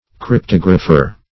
cryptographer \cryp*tog"ra*pher\ (kr?p-t?g"r?-f?r), n.